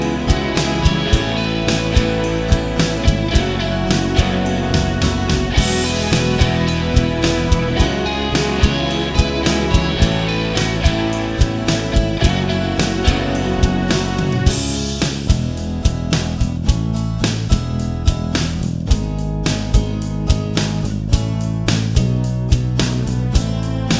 no Backing Vocals Rock 3:30 Buy £1.50